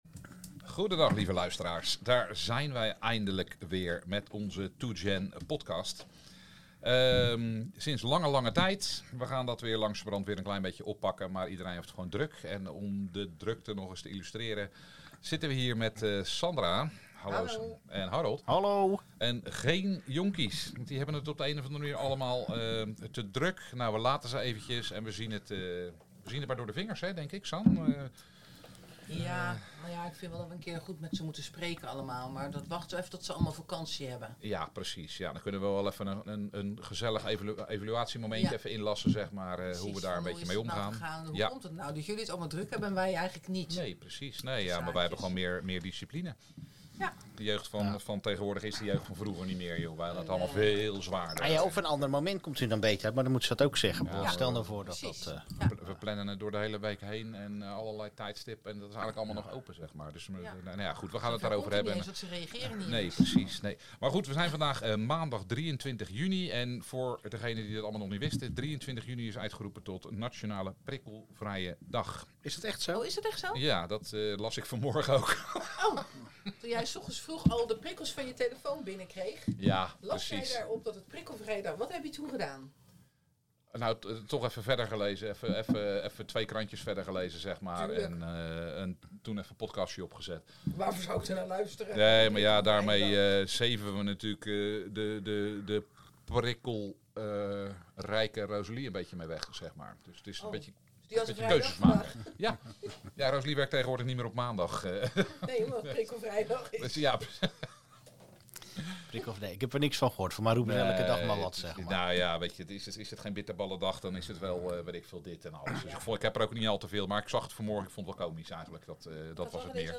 In de eerste aflevering twee vaders, één dochter en één zoon.